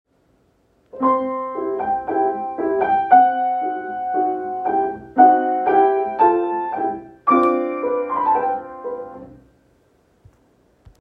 • First, be aware that although the music is written for grand staff, the bottom staff has a treble clef.